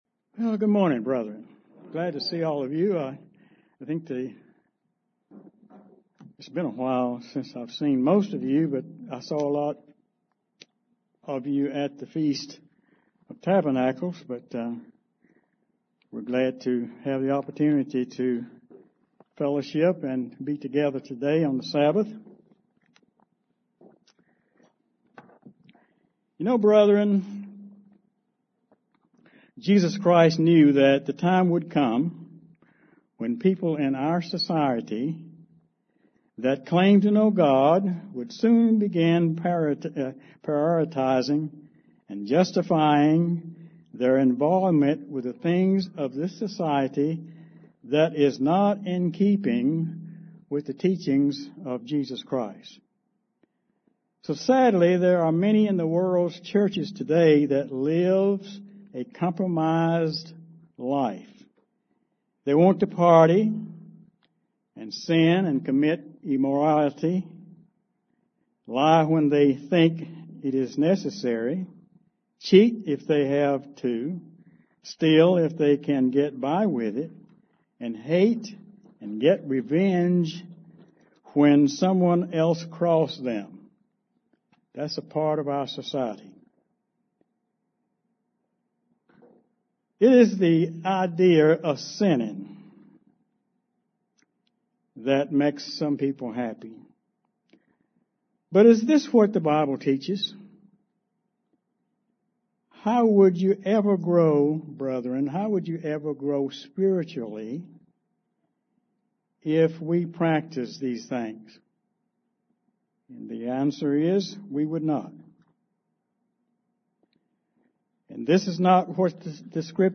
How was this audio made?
Given in Charlotte, NC